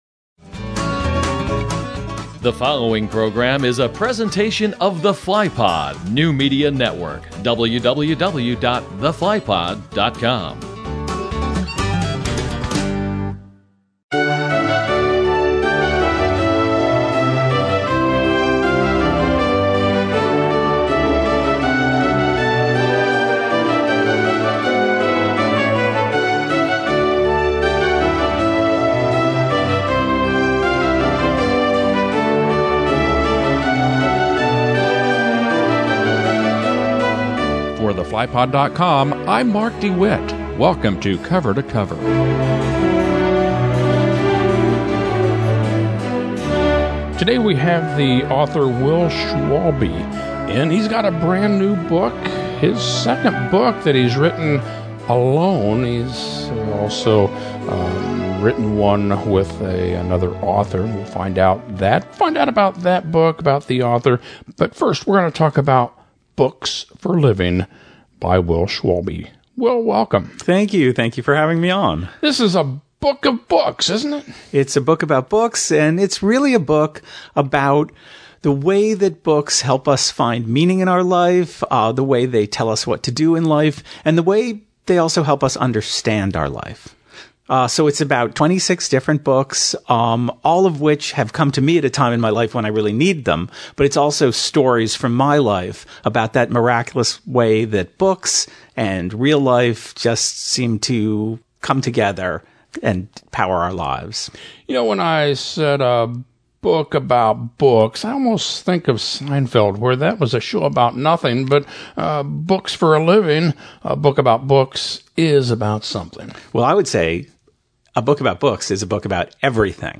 interviews author Will Schwalbe — they discuss his book entitled BOOKS FOR LIVING